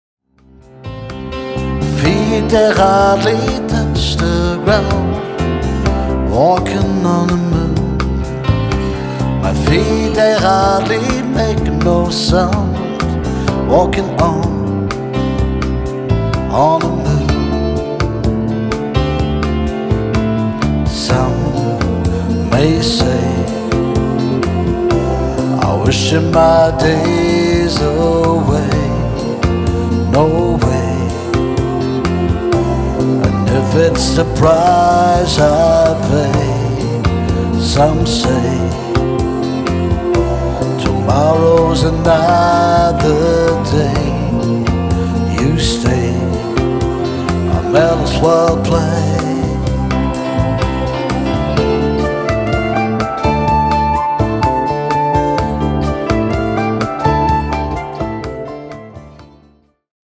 Demoaufnahmen